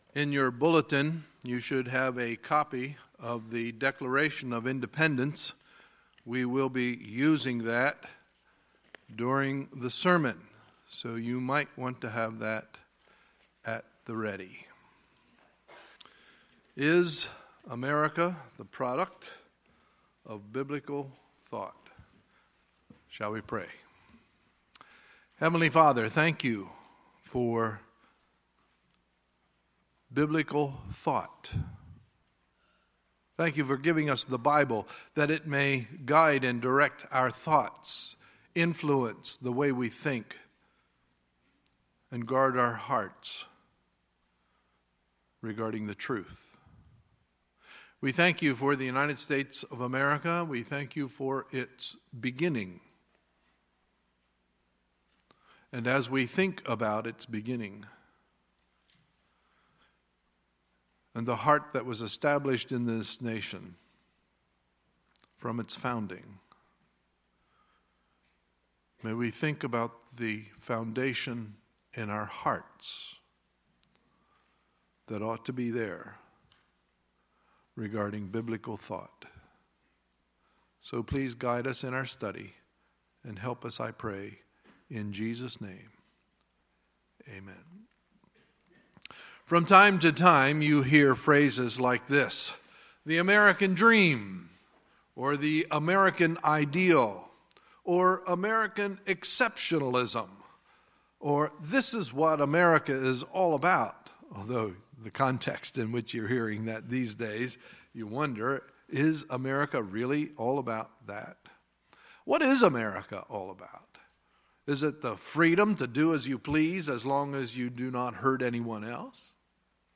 Sunday, July 2, 2017 – Sunday Morning Service